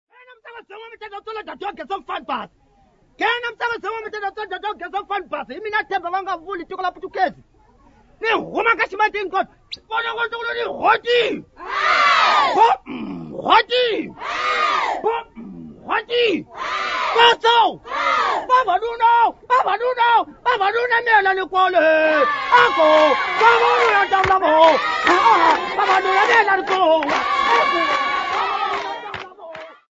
Tonga/ Hlanguni (Performers)
Folk music--Africa
Hand-clapping music
Folk dance music
Whistles
field recordings
Dance song for Masesa dance with 2 cylindrical drums, whistles and clapping